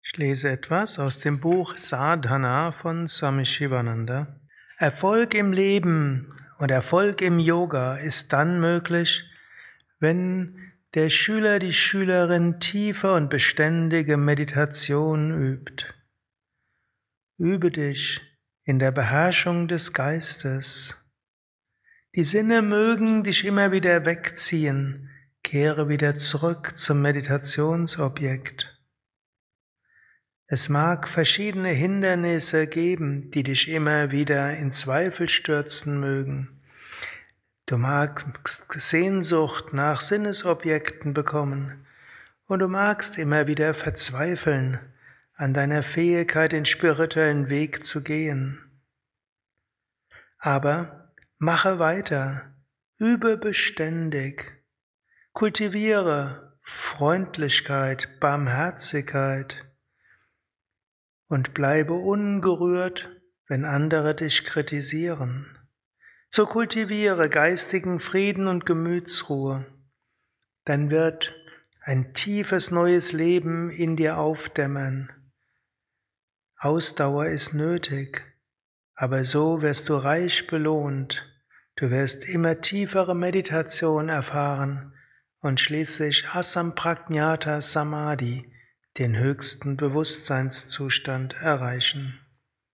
Dies ist ein kurzer Vortrag als